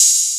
Open Hat (Aint Coming Back).wav